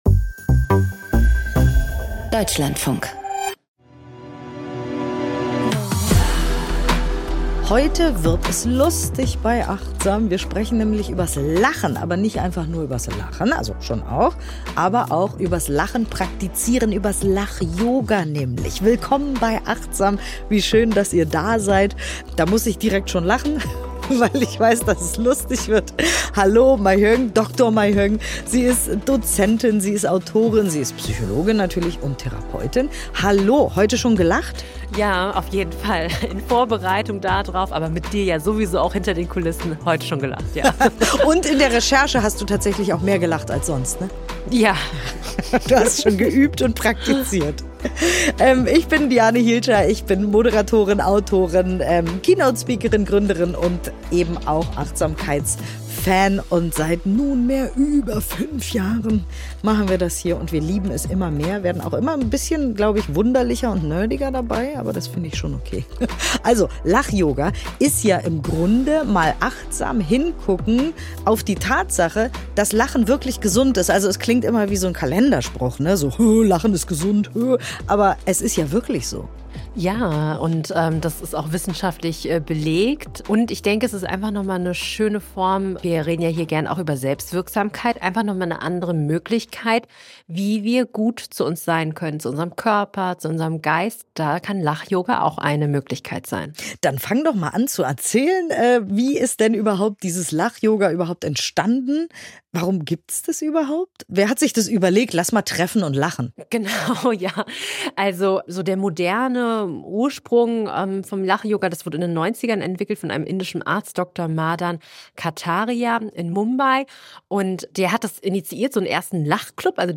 ********** An dieser Stelle findet ihr die Übung: 00:25:10 - Geleitete Meditation, um das eigenen Lächeln zu kultivieren.